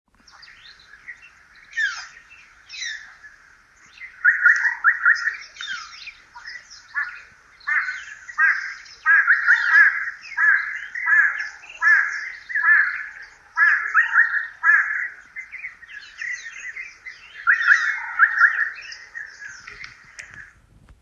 bird-sounds.m4a